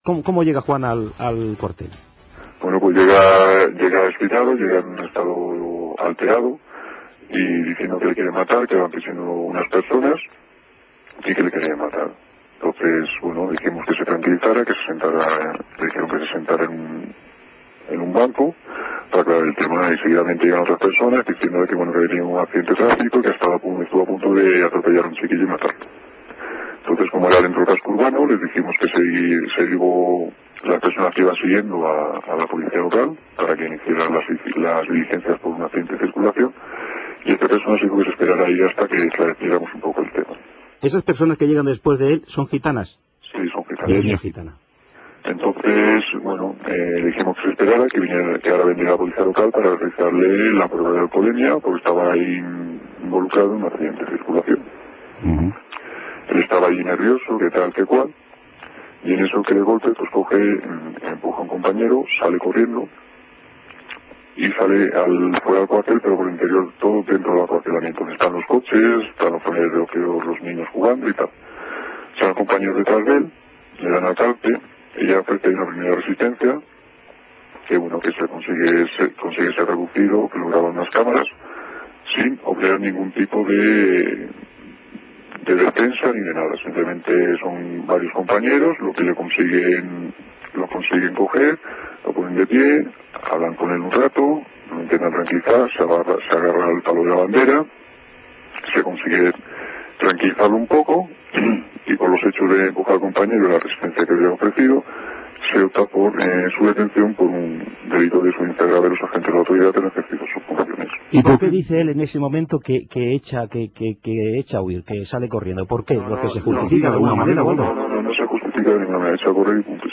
Entrevista en exclusiva a un Guardia Civil de Roquetas de Mar, Cadena Cope.